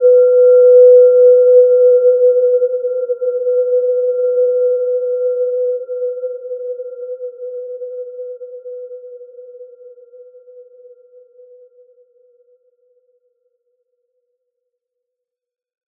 Gentle-Metallic-4-B4-p.wav